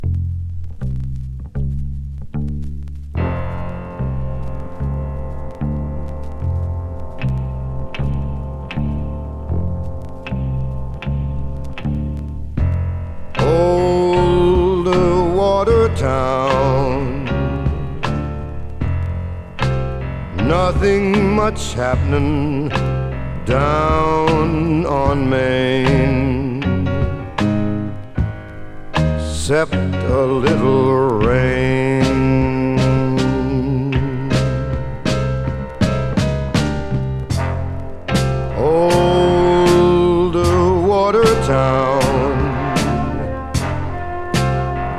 Jazz, Pop, Vocal, Ballad　USA　12inchレコード　33rpm　Stereo